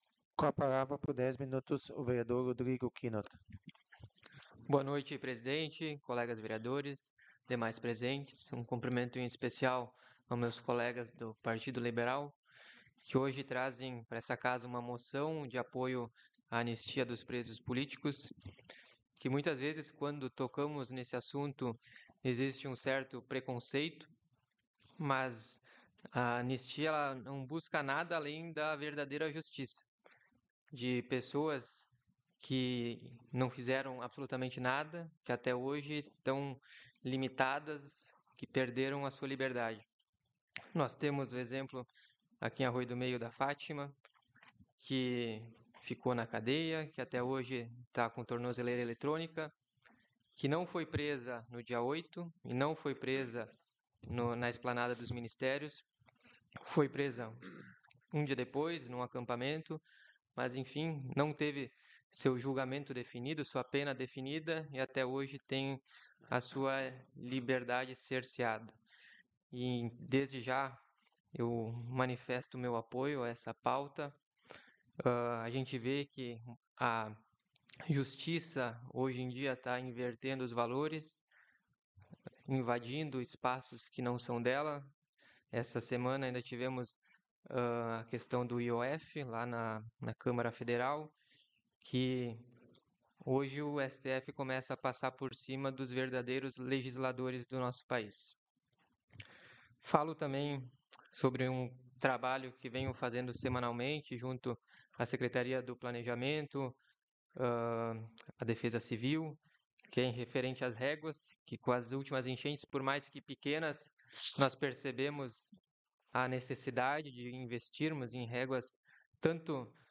Áudio das Sessões Vereadores